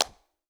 Clap11.wav